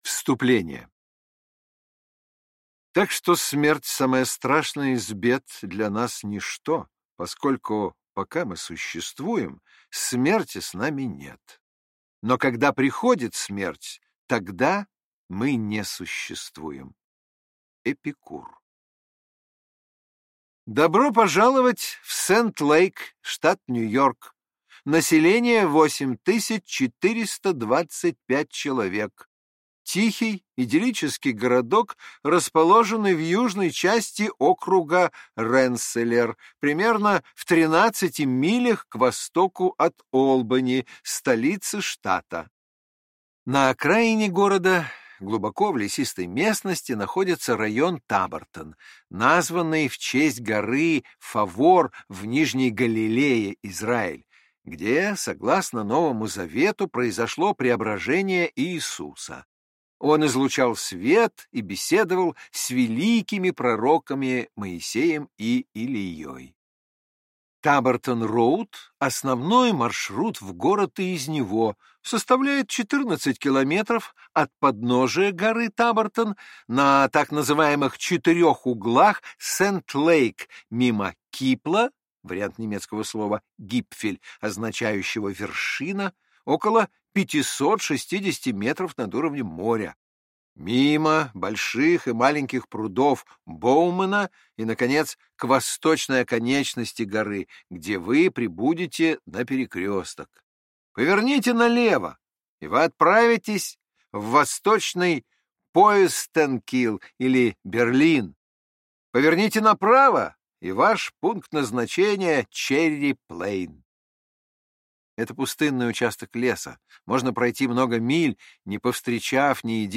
Аудиокнига Убийство у Тилз-Понд. Реальная история, легшая в основу «Твин Пикс» | Библиотека аудиокниг